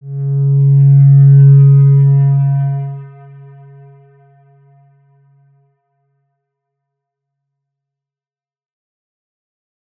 X_Windwistle-C#2-mf.wav